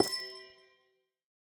Minecraft Version Minecraft Version snapshot Latest Release | Latest Snapshot snapshot / assets / minecraft / sounds / block / amethyst_cluster / place4.ogg Compare With Compare With Latest Release | Latest Snapshot